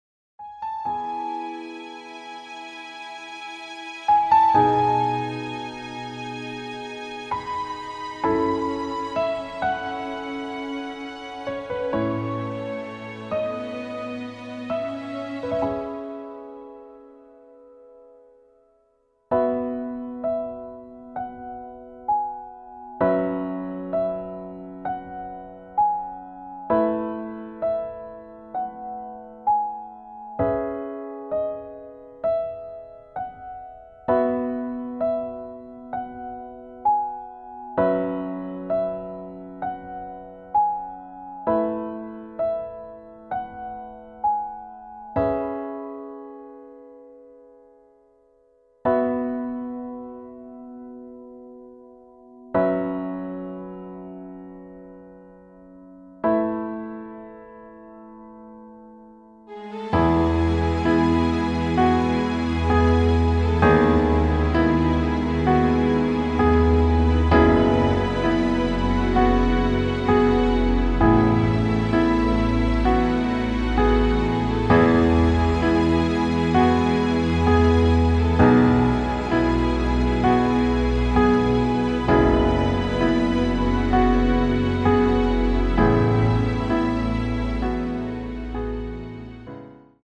• Tonart:  D Dur, E Dur, G Dur
• Art: Klavier Streicher Version
• Das Instrumental beinhaltet keine Leadstimme
• Alle unsere DEMOS sind mit einem Fade-In/Out.
Klavier / Streicher